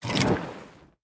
torch.ogg